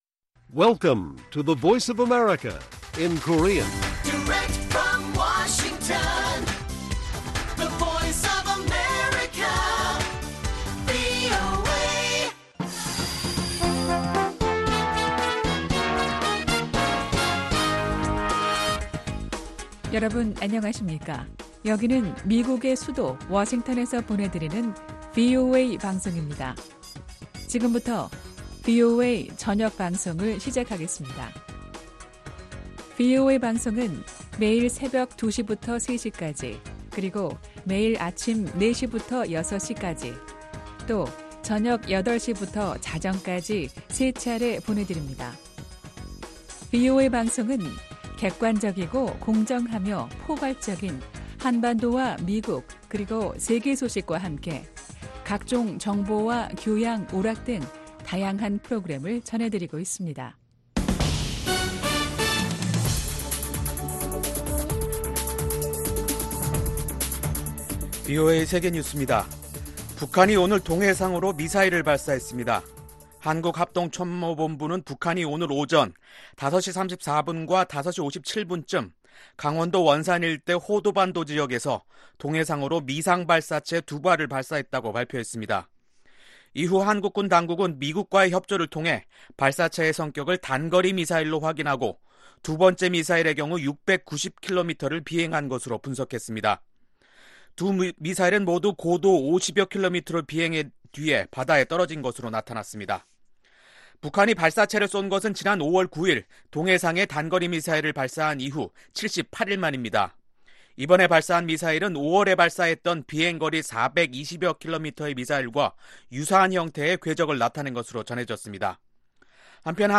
VOA 한국어 간판 뉴스 프로그램 '뉴스 투데이', 2019년 7월 25일 1부 방송입니다. 한국 군 당국은 25일 북한이 두 번째로 발사한 단거리 미사일의 비행거리가 690여 km로 파악됐다고 밝혔습니다. 유엔주재 북한대표부는 미국이 안보리 대북 결의 이행을 유엔 회원국들에 촉구한 데 대해 “제재를 선동하고 있다”고 비난했습니다.